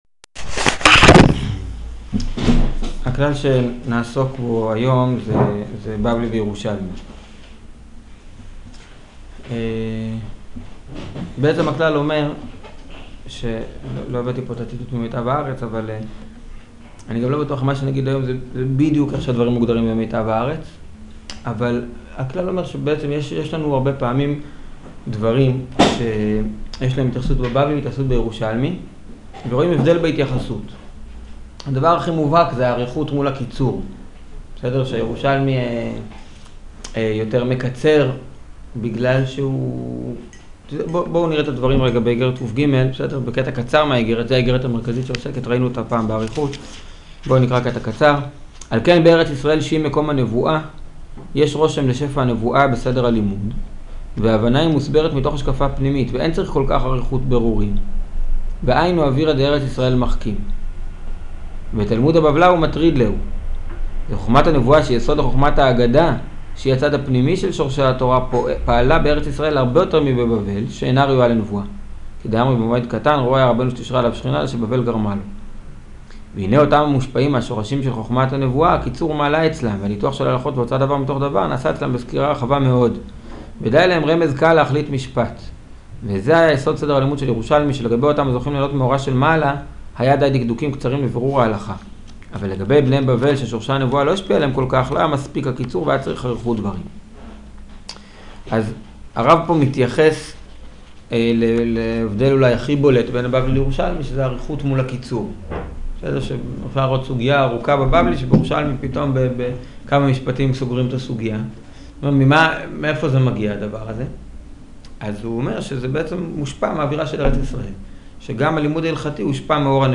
שיעור הבדלים בין בבלי לירושלמי